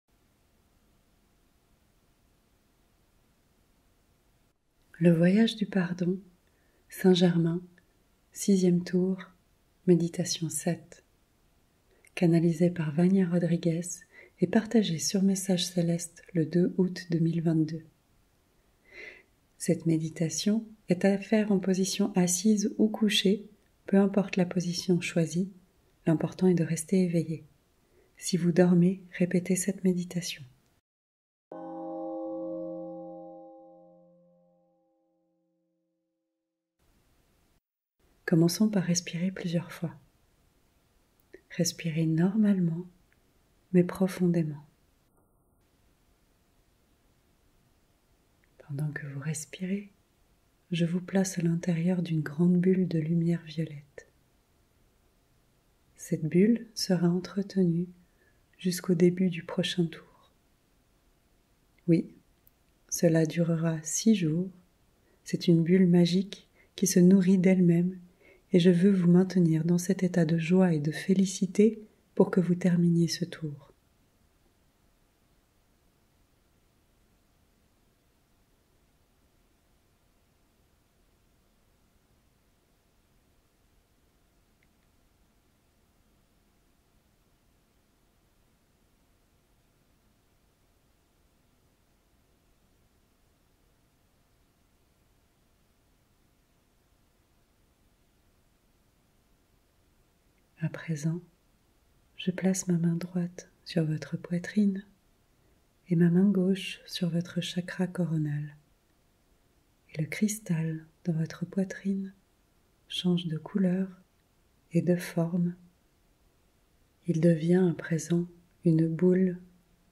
Tour 6 - Méditation 7 - sans_pub